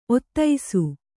♪ ottaisu